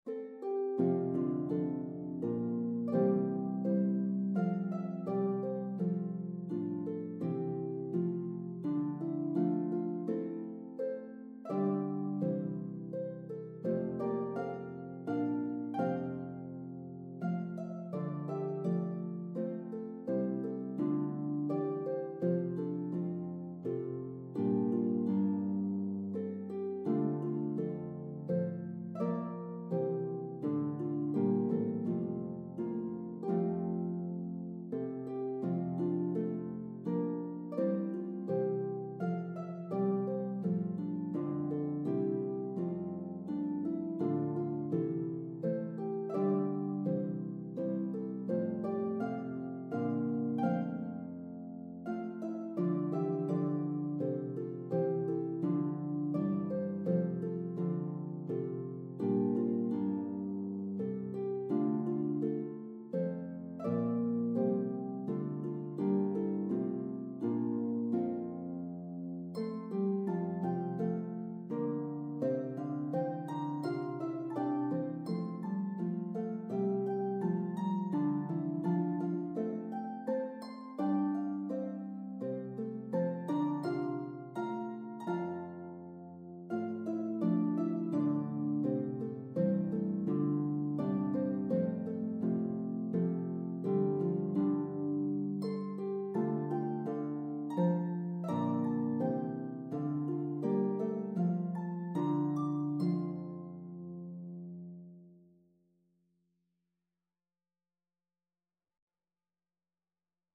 Dynamics are clearly marked.